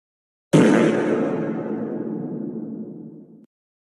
Peido Eco
peido-eco.mp3